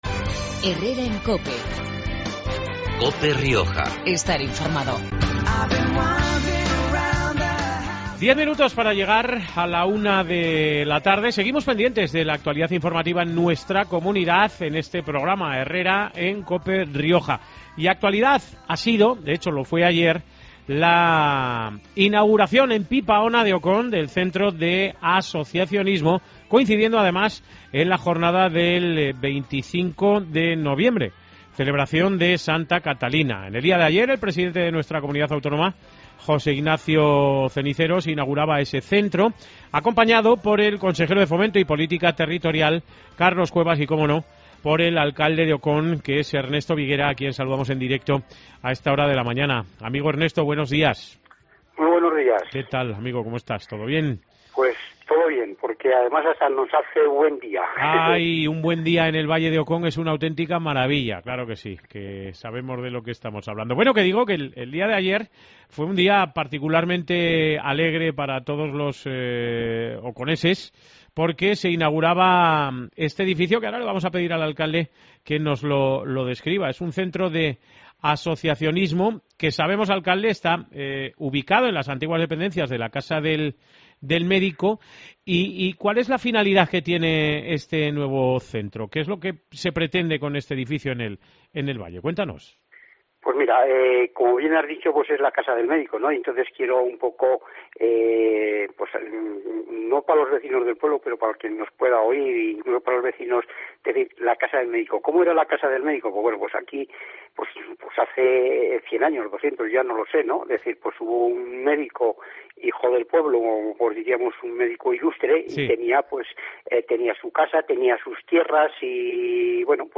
Herrera y Mediodía en Cope Rioja Baja (lunes 26 noviembre). Programa de actualidad y entrevistas, hoy con Ernesto Viguera, alcalde de Ocón.
El alcalde de Ocón, Ernesto Viguera, agradecía este mediodía en Cope el apoyo económico del Gobierno de La Rioja al medio rural.